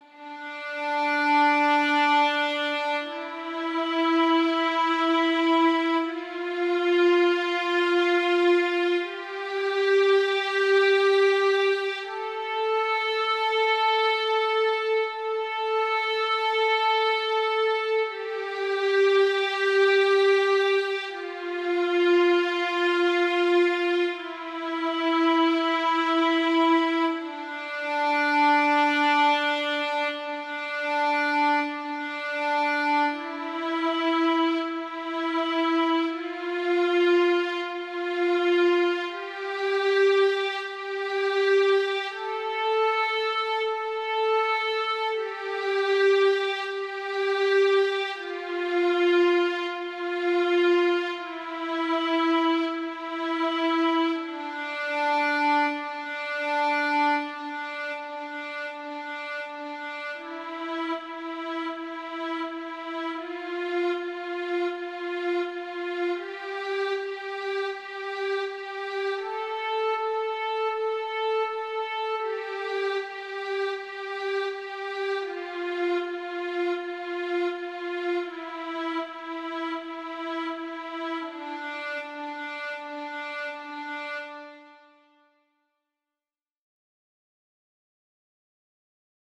D선 연습 > 바이올린 | 신나요 오케스트라